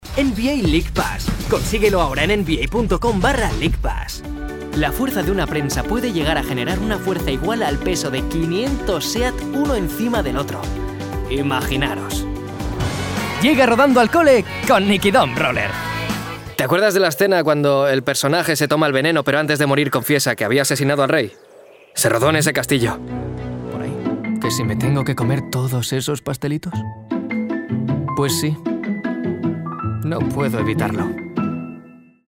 Young professional Spanish voice talent specialised in voice acting and film dubbing. Extremely versatile voice.
kastilisch
Sprechprobe: Werbung (Muttersprache):